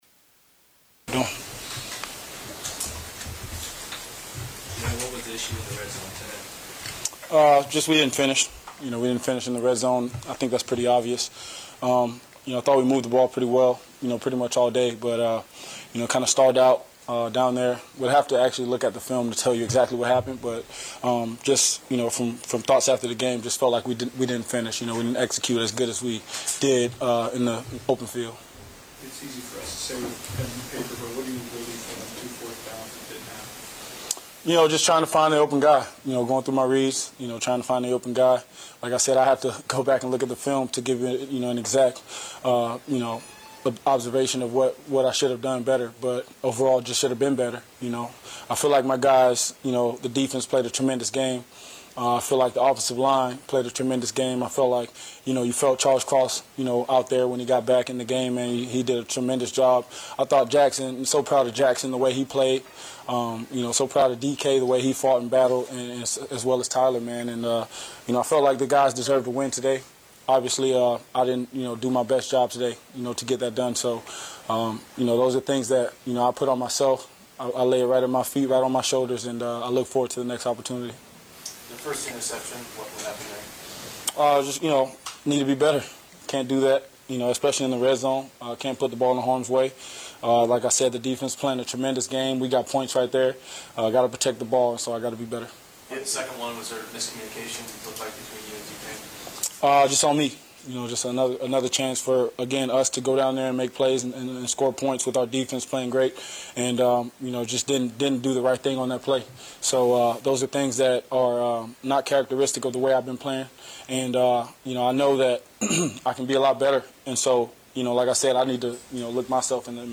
Seahawks QB Geno Smith talks to media after 17-13 loss at Cincinnati on Sunday, October 15, Week 6